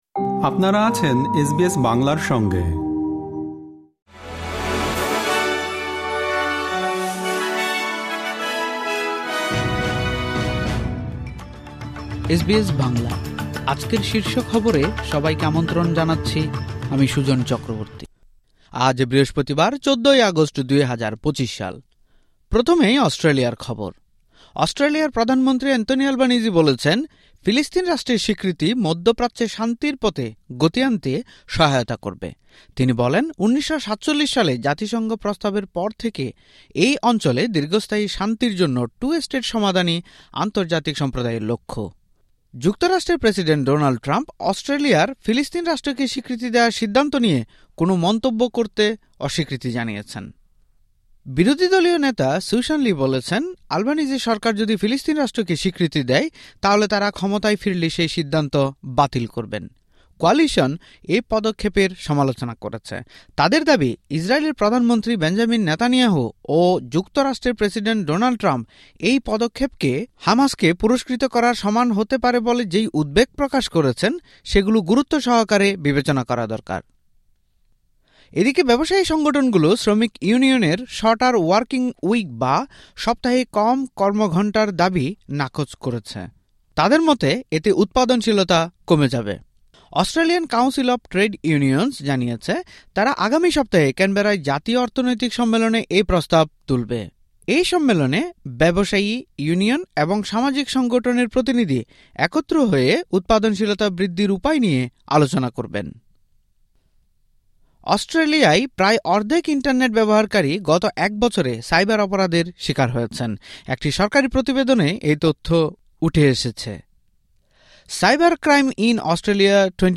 আজকের শীর্ষ খবর